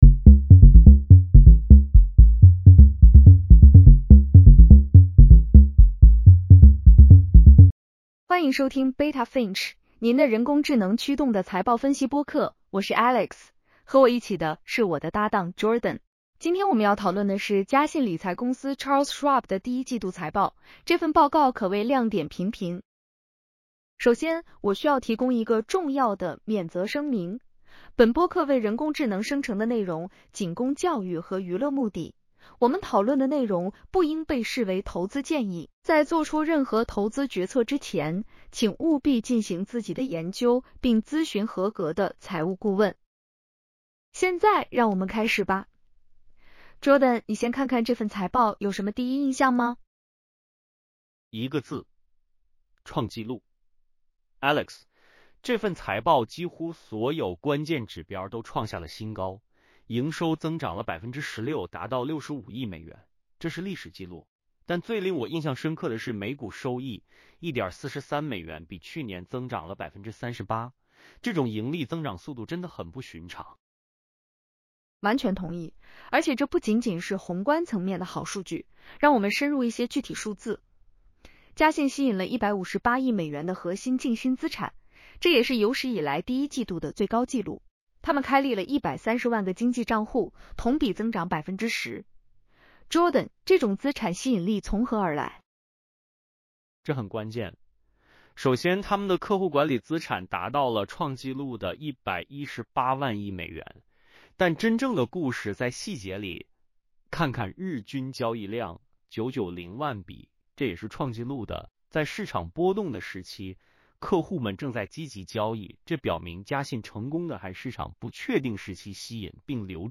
Charles Schwab Q1 2026 earnings call breakdown.